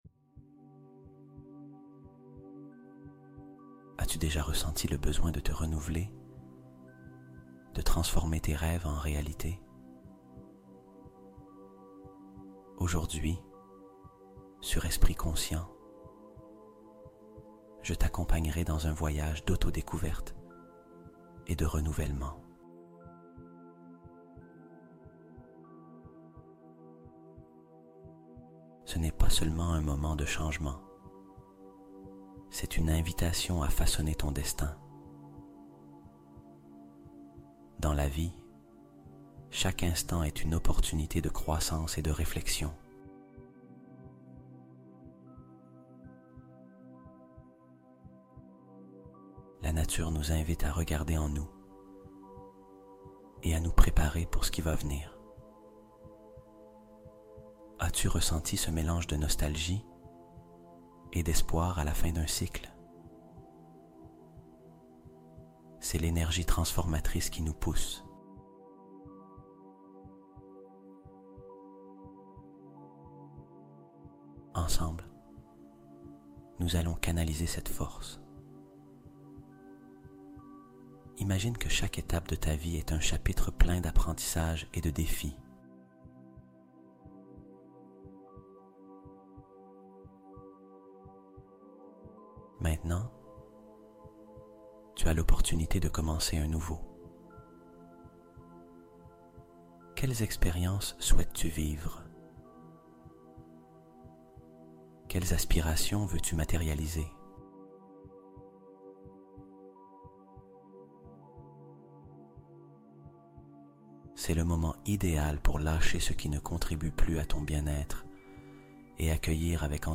Intentions Claires : Méditation sur la bienveillance universelle